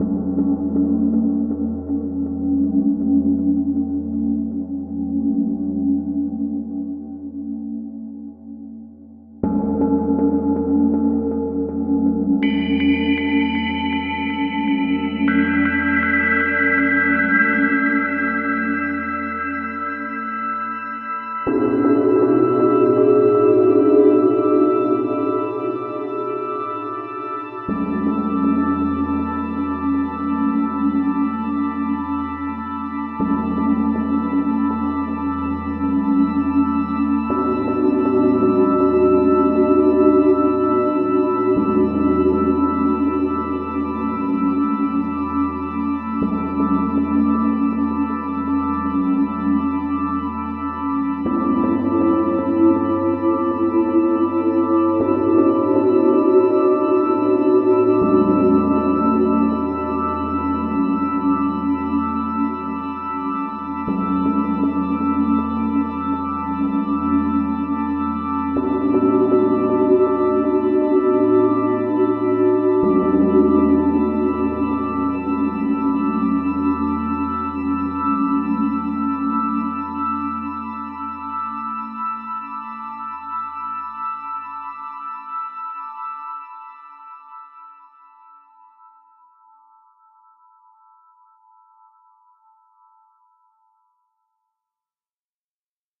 flute, sax, piano, electronics